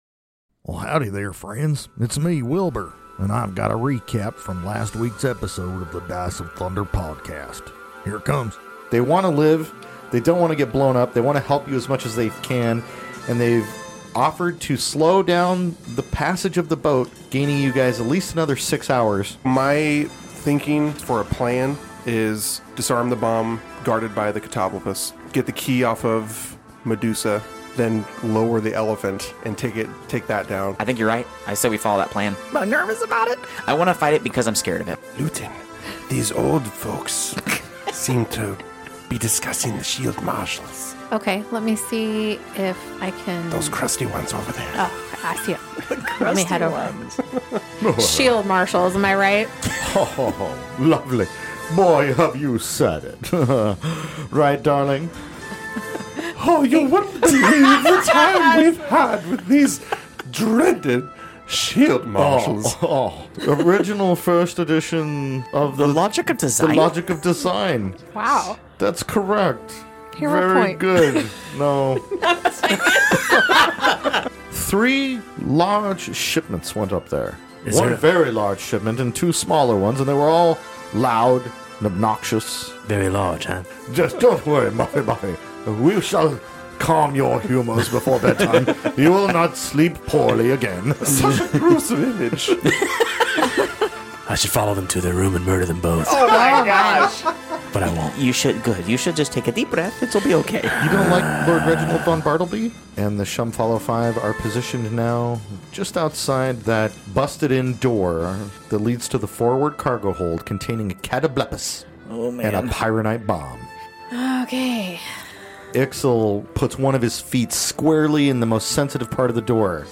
A Pathfinder 2nd Edition actual play podcast suitable for all ages! Join us as we play through the Outlaws of Alkenstar adventure path published by Paizo Inc. as part of the Pathfinder 2nd Edition tabletop role-playing game.
The show consists of new players and an experienced GM.